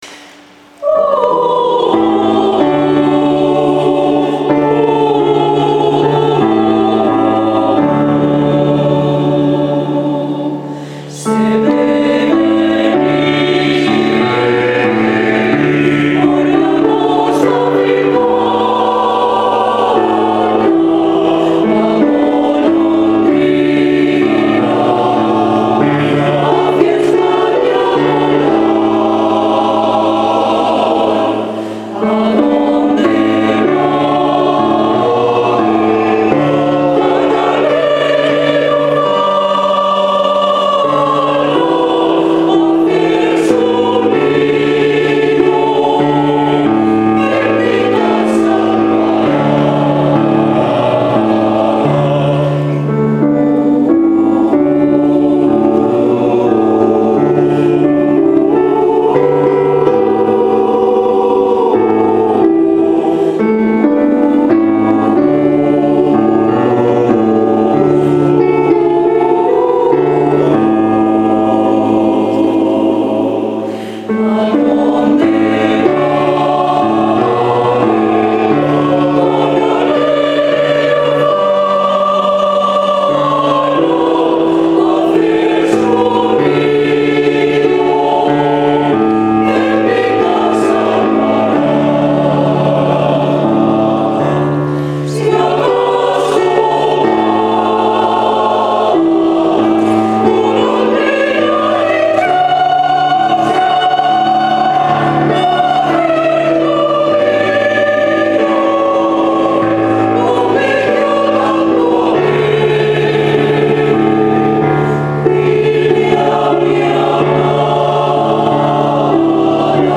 Autoridades municipales asistieron el pasado 25 de julio a la tradicional misa en honor al Patrón de Totana, Santiago Apóstol, que se celebró por la tarde en la parroquia de Santiago El Mayor de esta localidad.
y cantada por la Coral Santiago de Totana.
Además, a continuación tuvo lugar un concierto de habaneras a cargo de la Coral Santiago.